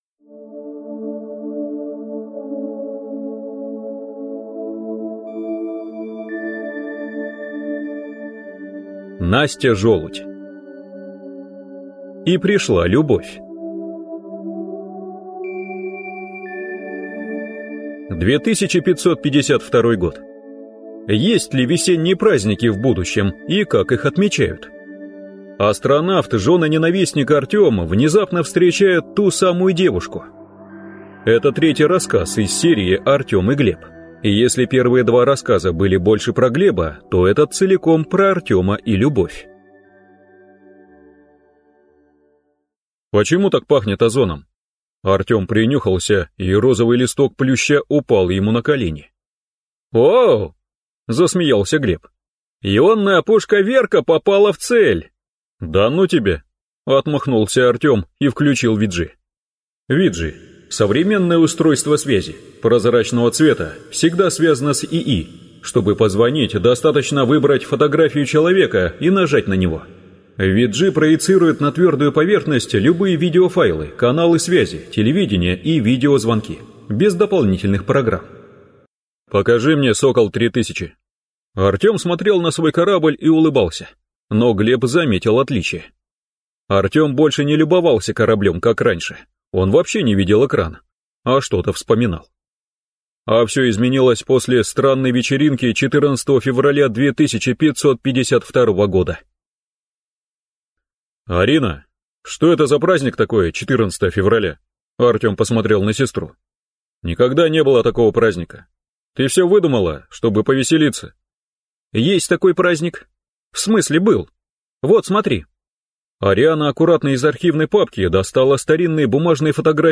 Аудиокнига И пришла любовь…